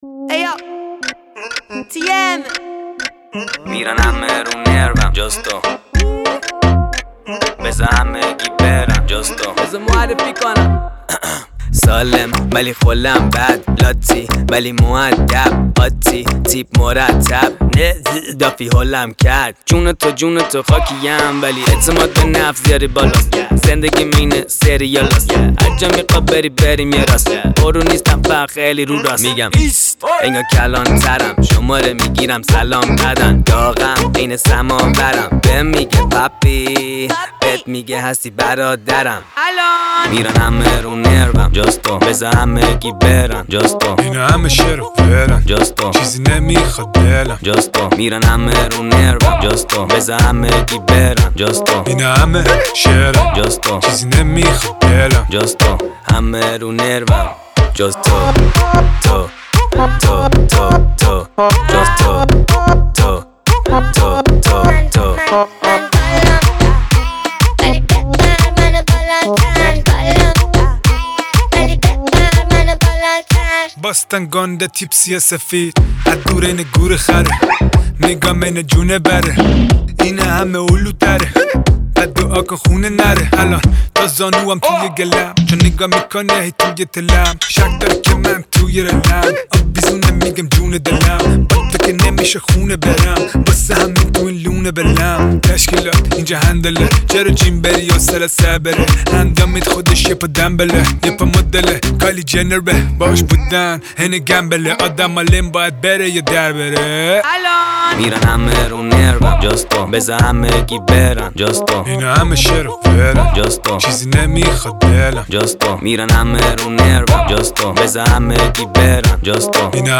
پر انرژی